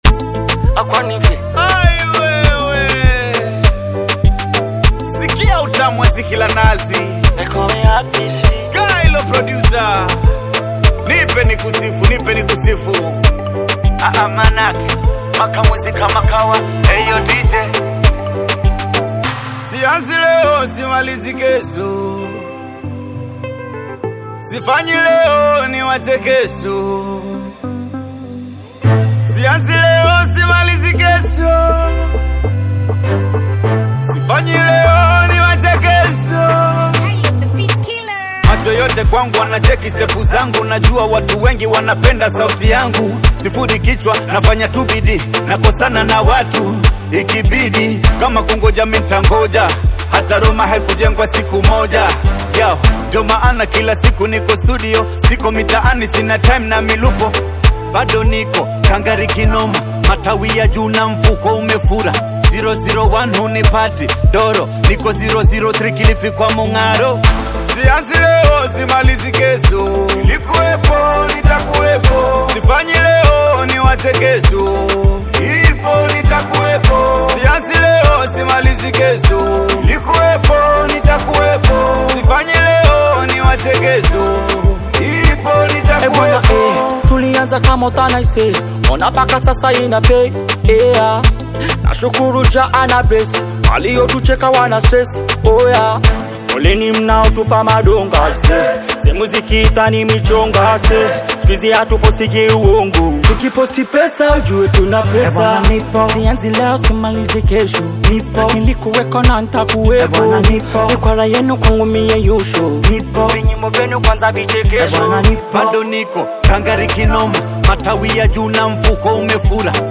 Kenyan Music Amapiano Afrobeats